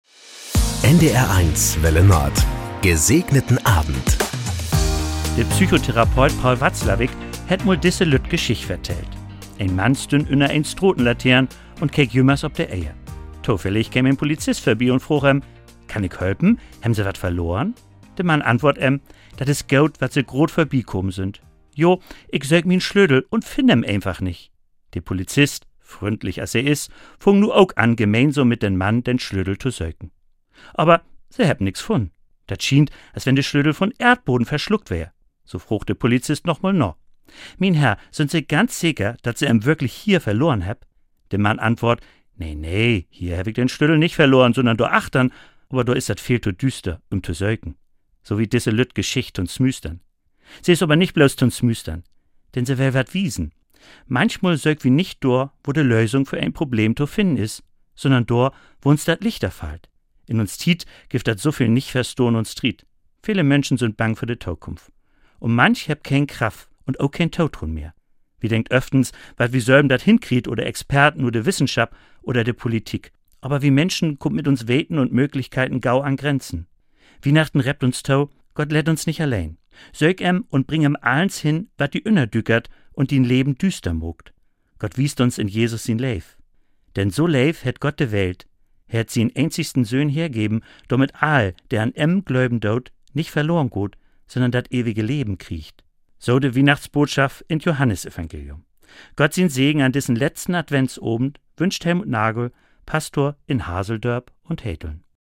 Das gute Wort zum Feierabend auf NDR 1 Welle Nord mit den Wünschen für einen "Gesegneten Abend". Von Sylt oder Tönning, Kiel oder Amrum kommt die Andacht als harmonischer Tagesabschluss. Täglich um 19.04 Uhr begleiten wir Sie mit einer Andacht in den Abend - ermutigend, persönlich, aktuell, politisch, tröstend.